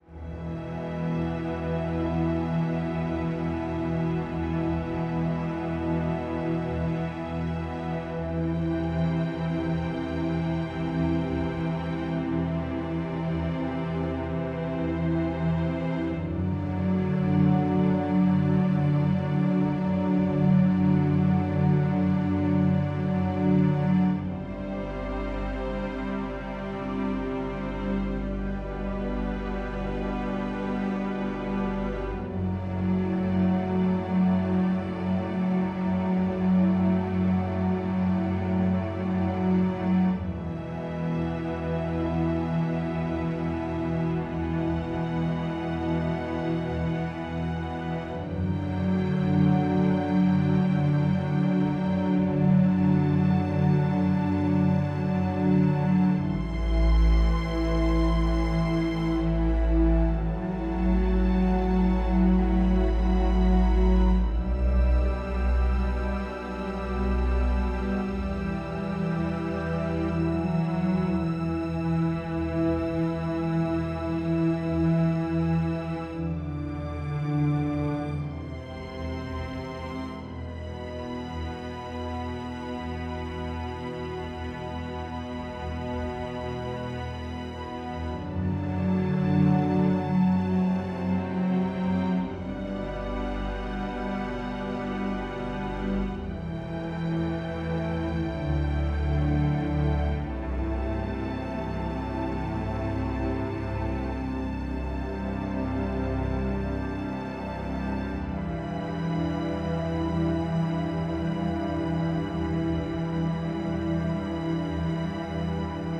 A musical motif that builds as the main character “I” writes in their journal throughout the show.